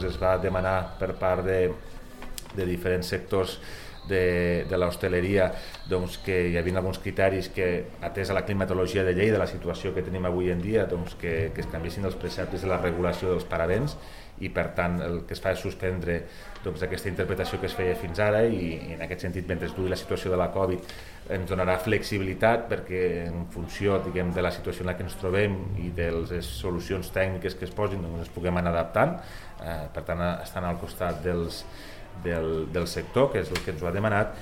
Fitxers relacionats Tall de veu del tinent d'alcalde Toni Postius sobre la Comissió Informativa per les Polítiques de Gestió Urbanística i Mobilitat i la modificació de l'Ordenança del Paisatge de Lleida (390.6 KB)